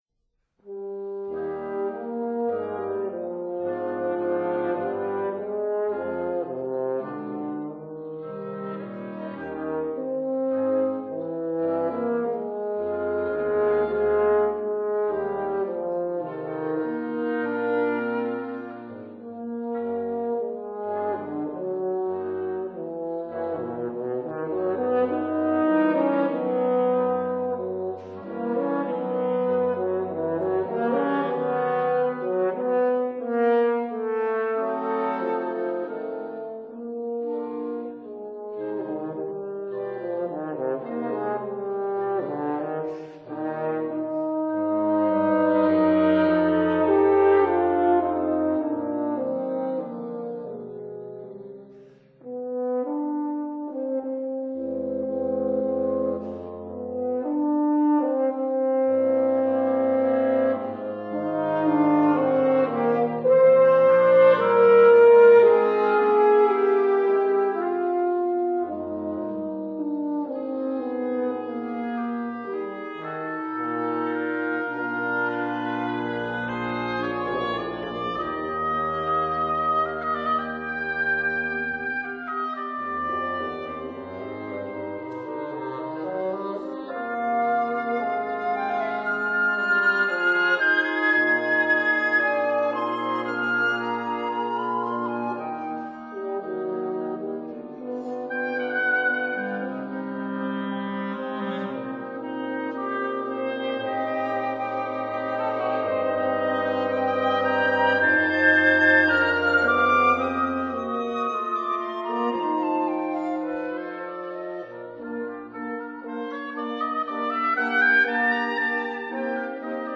flute
oboe
clarinet
horn
bassoon